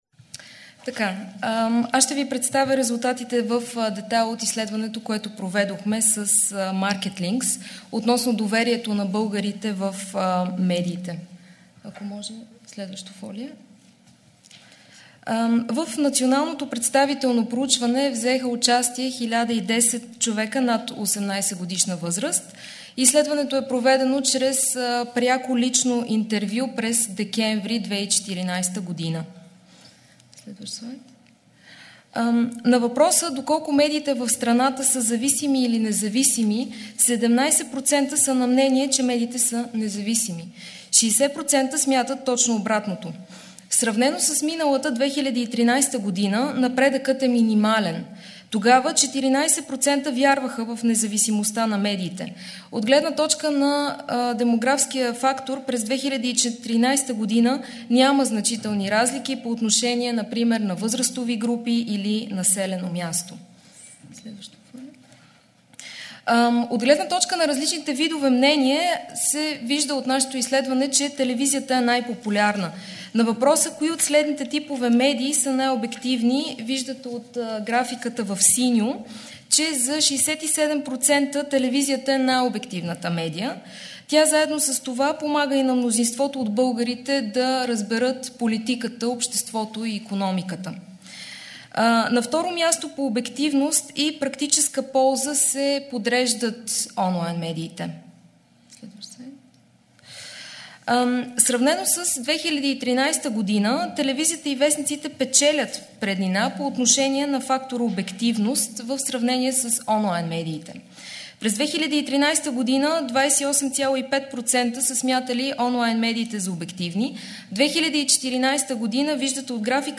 Was: Pressekonferenz der Stiftung Mediendemokratie (FMD) und des Medienprogramms Südosteuropa der Konrad-Adenauer-Stiftung (KAS)
Wo: Sofia, Presseklub der Nachrichtenagentur BTA